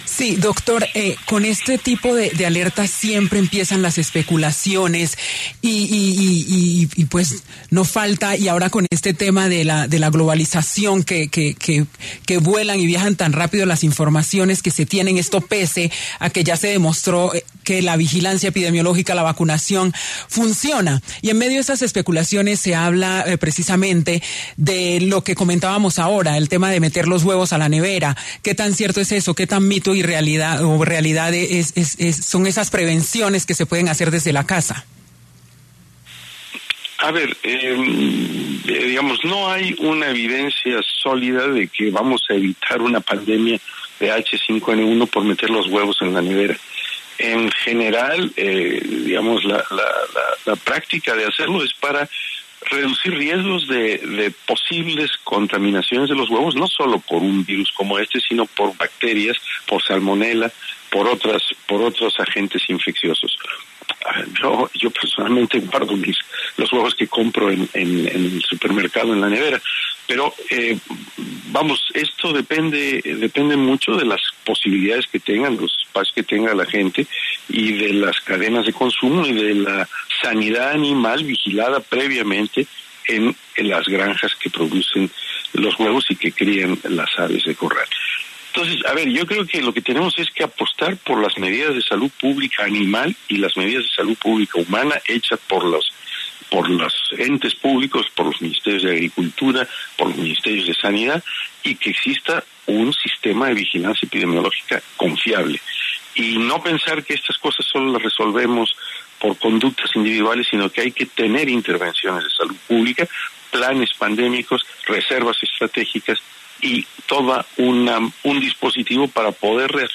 ¿Los huevos se guardan dentro o fuera de la nevera? Epidemiólogo responde